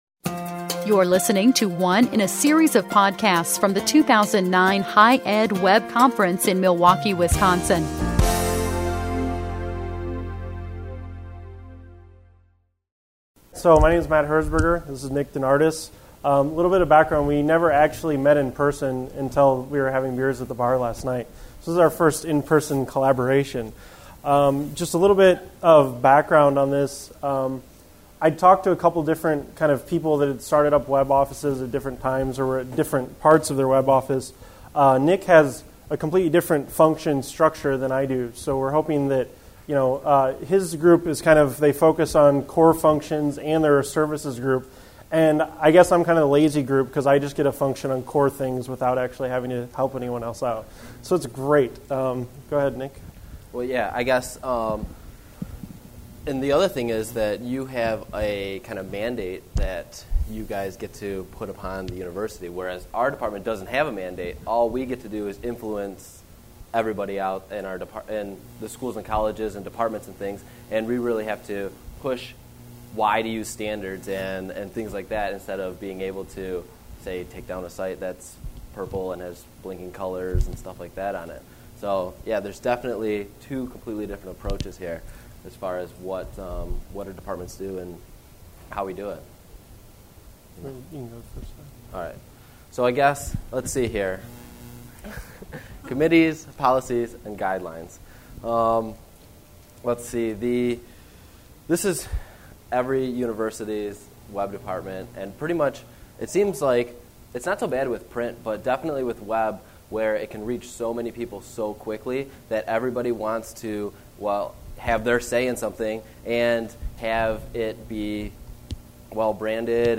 Session Details - HighEdWeb 2009 Conference: Open + Connected